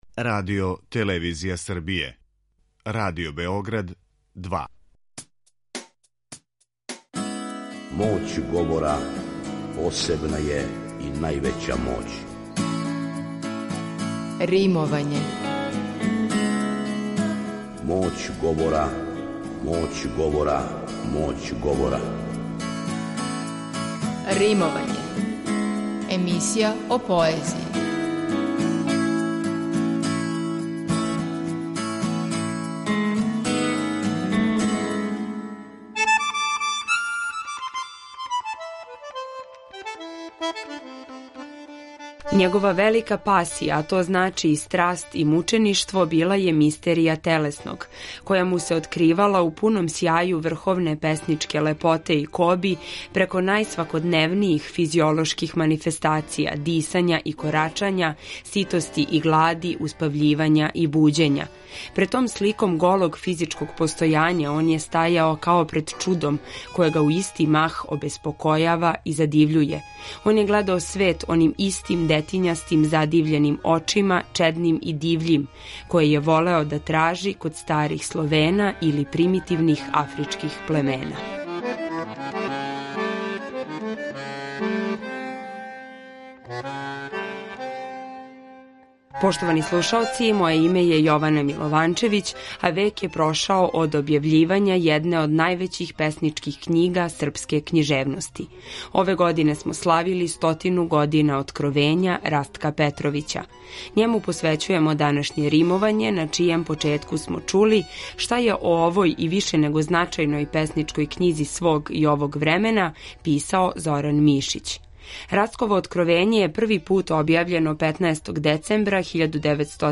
У данашњем Римовању Расткову поезију из „Откровења" и делове из његових познатих есеја говориће